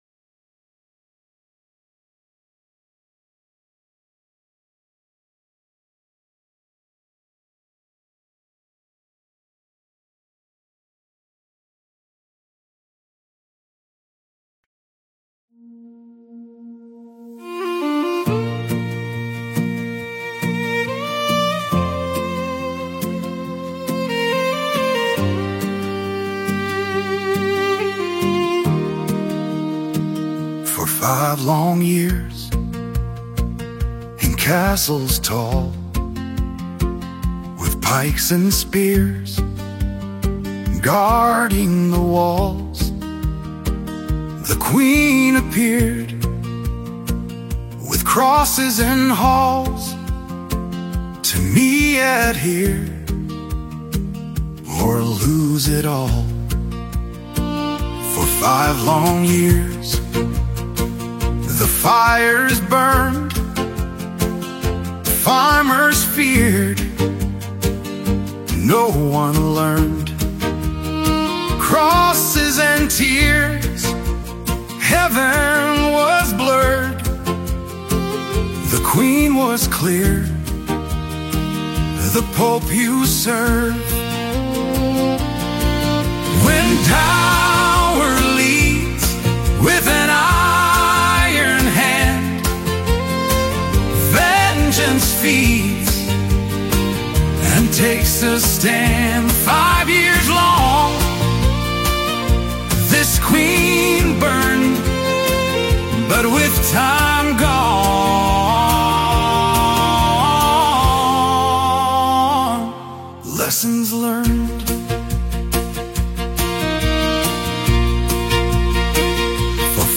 AI(Music)